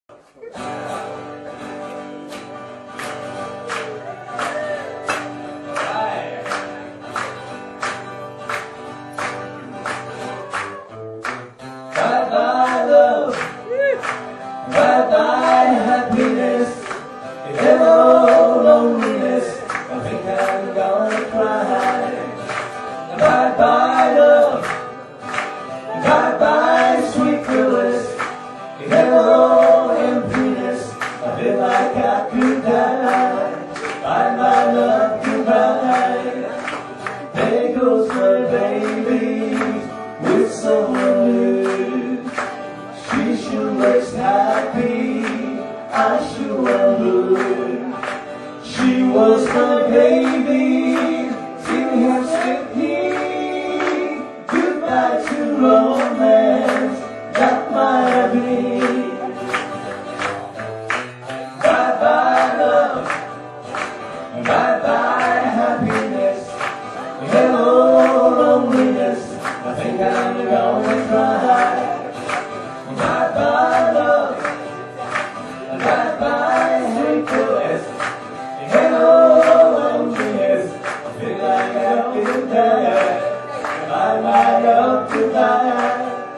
BACK IN TOWN LIVE
下の曲は当日のライブから収録したファーストステージの一部です。